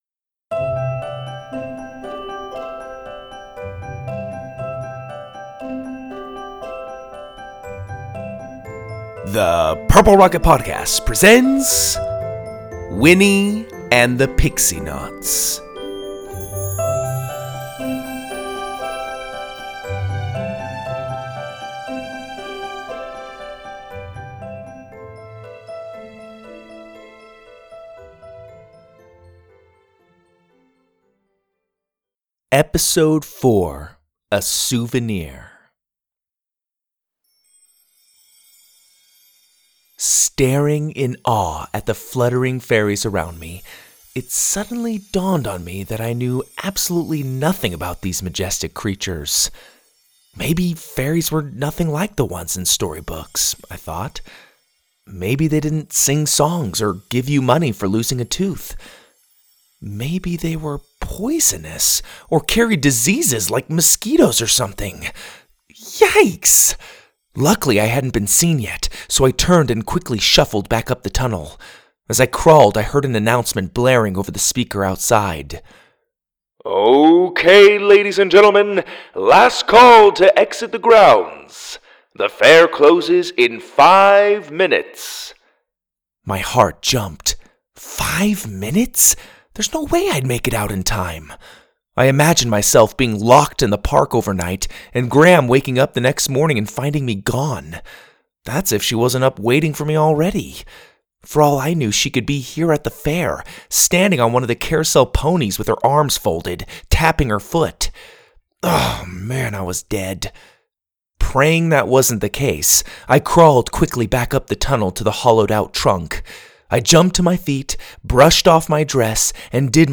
Stories For Kids, Kids & Family